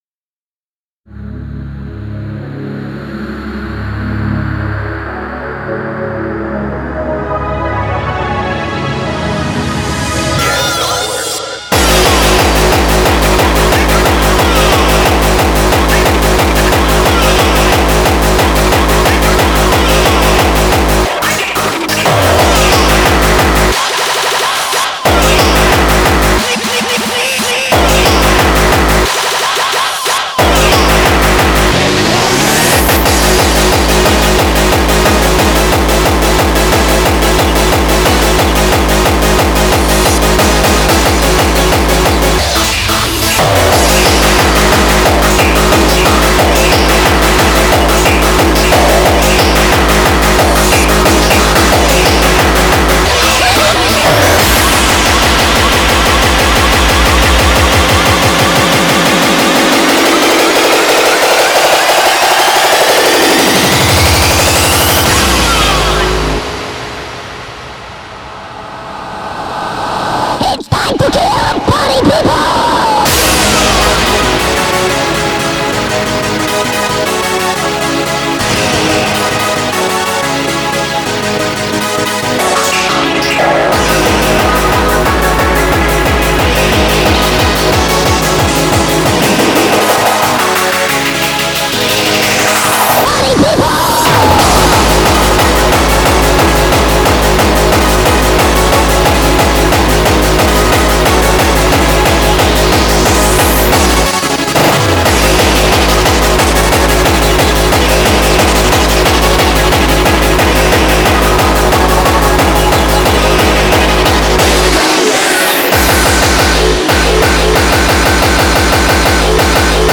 BPM90-180
Audio QualityPerfect (High Quality)
Genre: GABBAH.